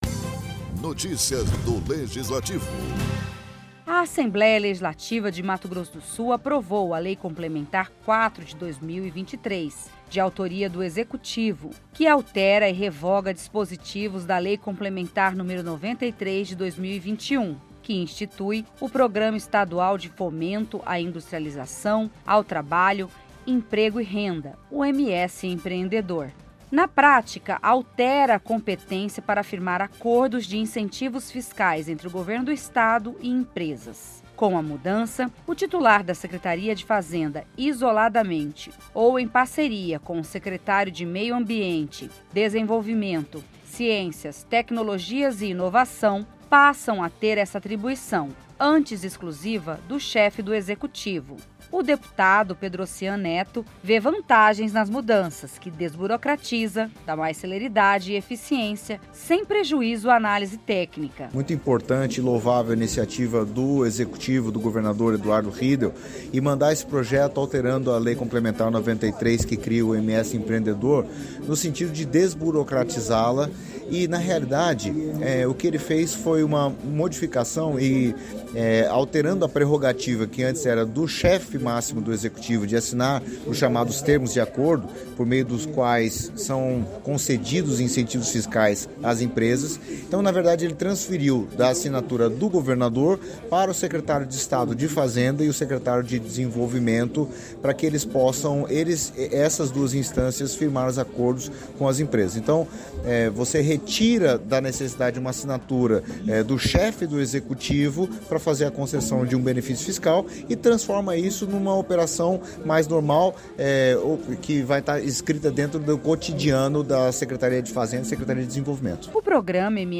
A onda de violência nas escolas tem preocupado os deputados estaduais de Mato Grosso do Sul, que repercutiram mais uma vez o tema durante sessão ordinária, após o lançamento do plano de segurança nas unidades educacionais do Estado, que prevê reforço da ronda policial nas escolas, com viaturas e até helicópteros, ampliação do monitoramento com novas câmeras e “botão do pânico” nas unidades para casos de emergência.